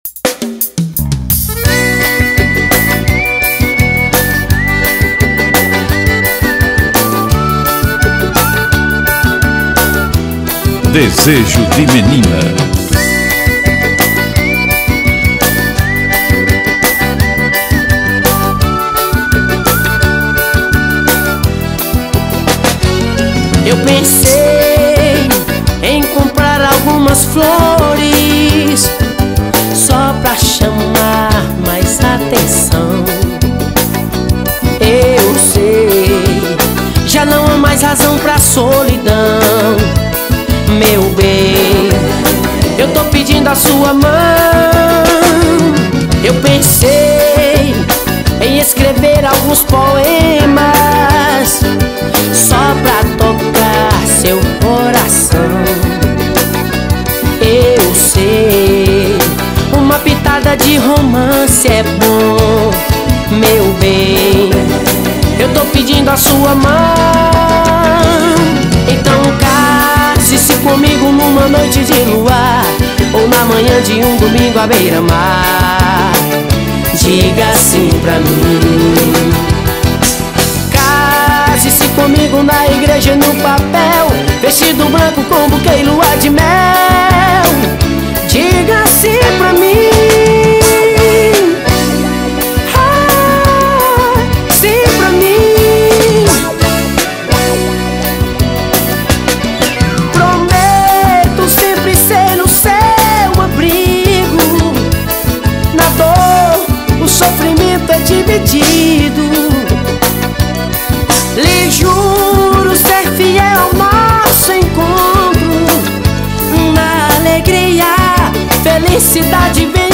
2024-12-19 11:38:27 Gênero: Forró Views